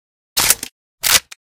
5caee9fba5 Divergent / mods / Armsel Protecta Reanimation / gamedata / sounds / weapons / librarian_striker / insert.ogg 8.7 KiB (Stored with Git LFS) Raw History
insert.ogg